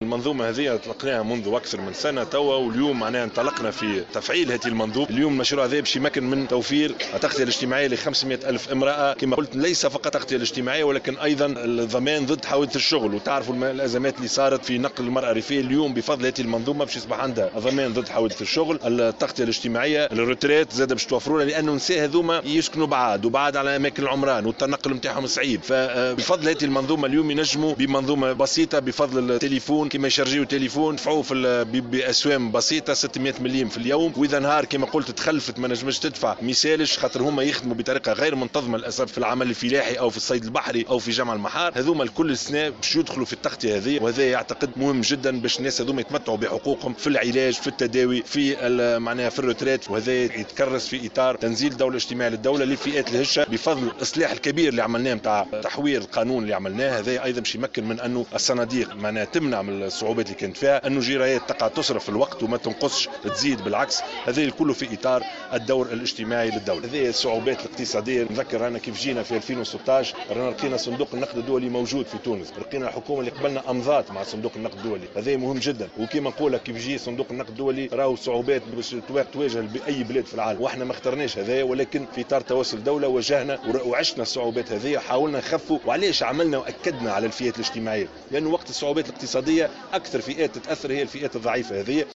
أكد يوسف الشاهد خلال زيارة أداها اليوم الجمعة الى منطقة النشع التابعة لمعتمدية الهوارية للاشراف على تسليم اول بطاقات العلاج المجاني الاولى لمنظومة "إحميني" أن هذه المنظومة ستمكن 500 ألف عاملة في المجال الفلاحي ومنتمية للوسط الريفي من الانتفاع بالتغطية الاجتماعية والضمان ضد حوادث الشغل والتقاعد.